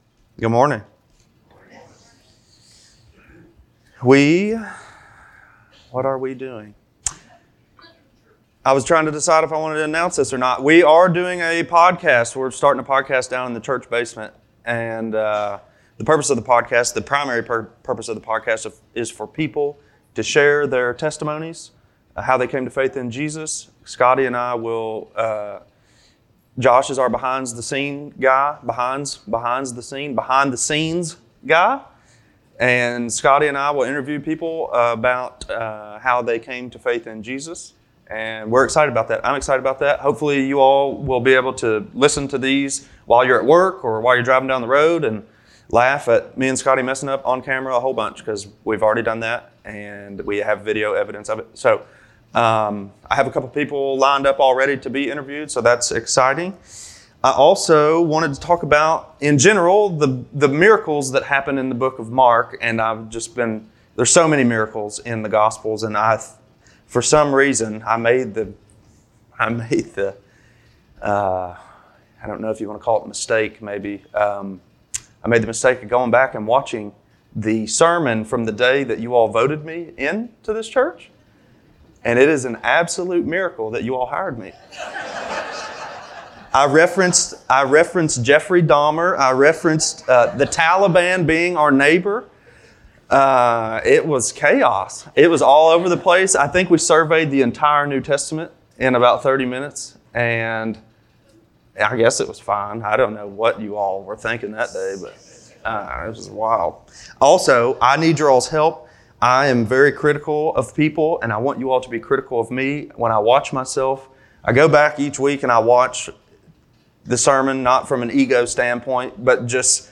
Sunday Morning Services | Belleview Baptist Church